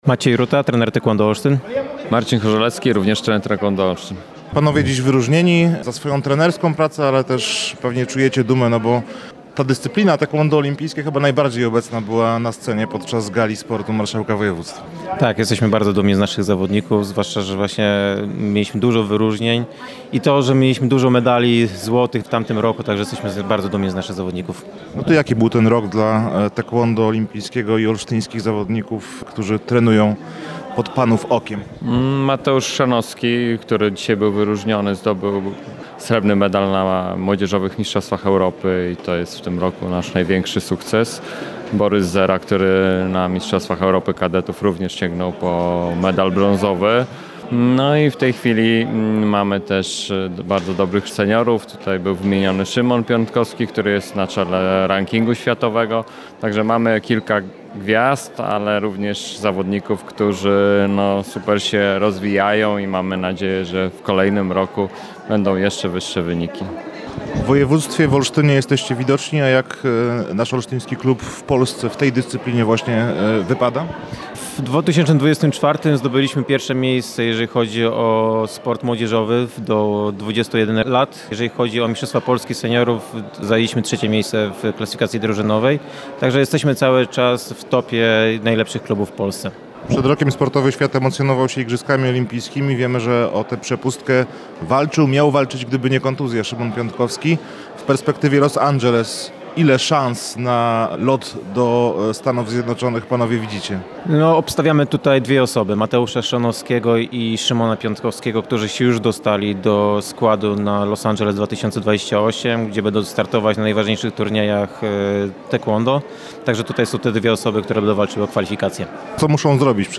– mówili nam po tegorocznej Gali Sportu trenerzy